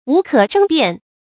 無可爭辯 注音： ㄨˊ ㄎㄜˇ ㄓㄥ ㄅㄧㄢˋ 讀音讀法： 意思解釋： 沒有什么可爭辨的。表示確實無疑。